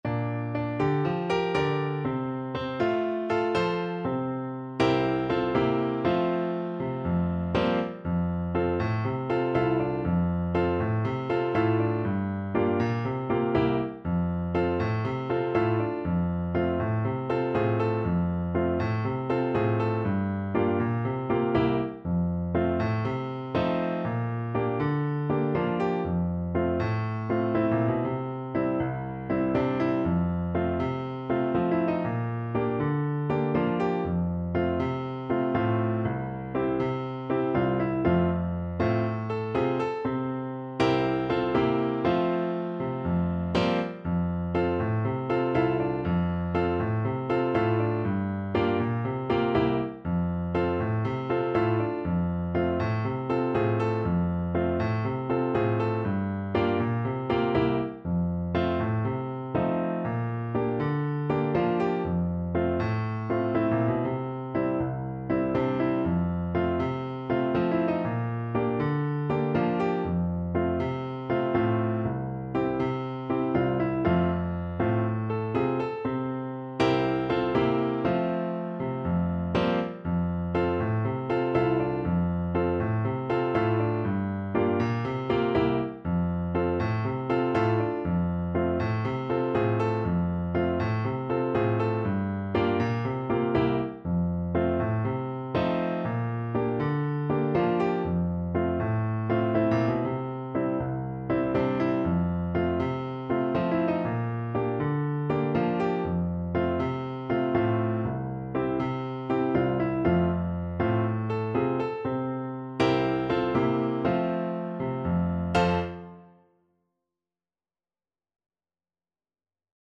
4/4 (View more 4/4 Music)
Calypso = 120